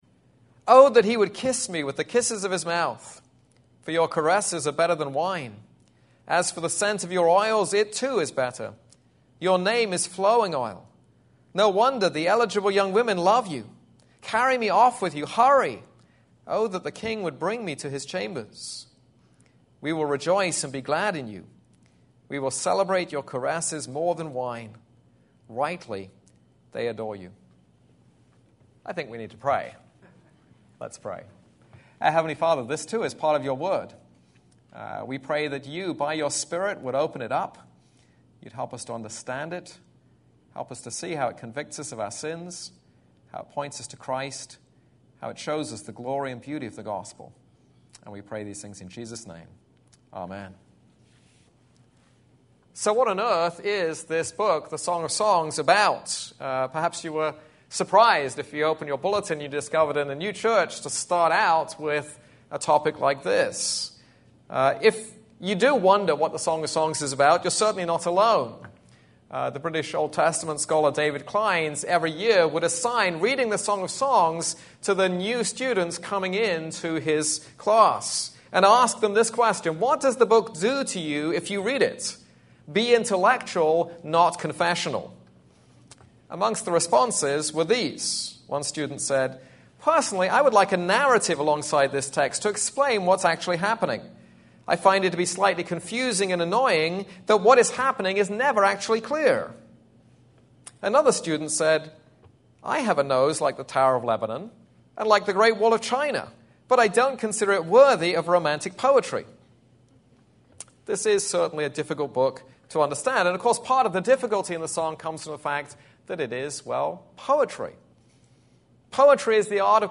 This is a sermon on Song of Songs 1:1-4.